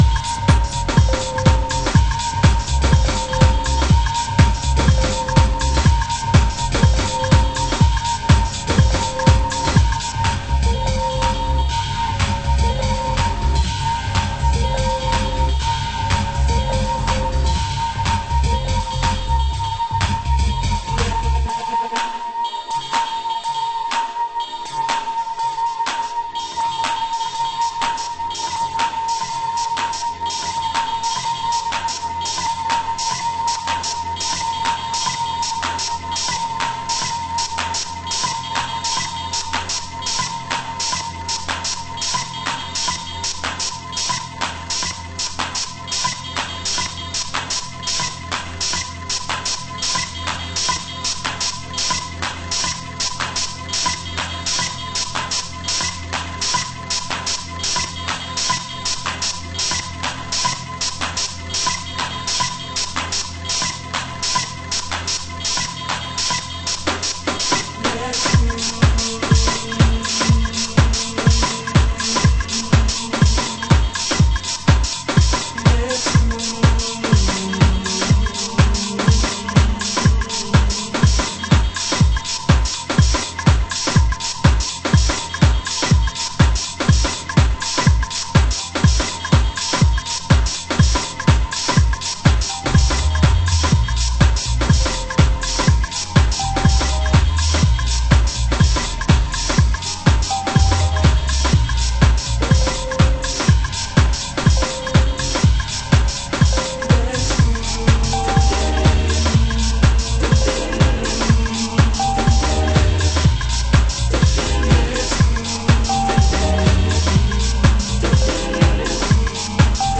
HOUSE MUSIC
盤質：少しチリノイズありますが概ね良好